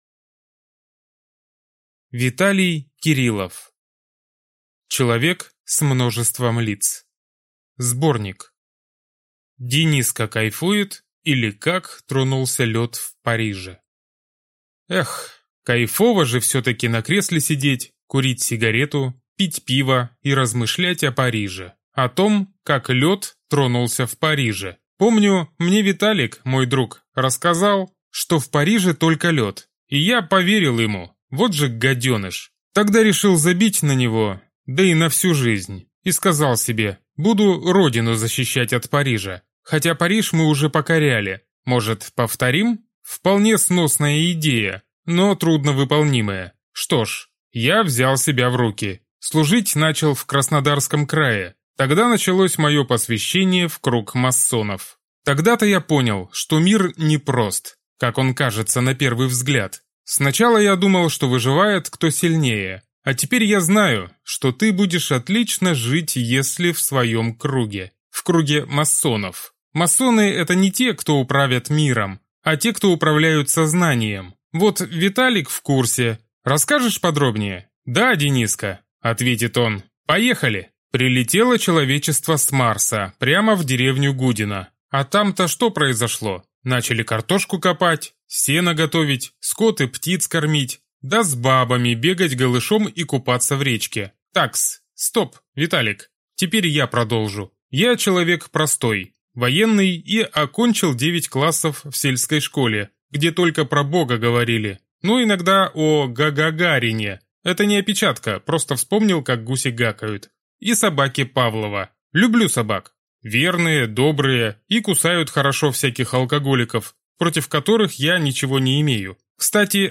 Аудиокнига Человек с множеством лиц. Сборник | Библиотека аудиокниг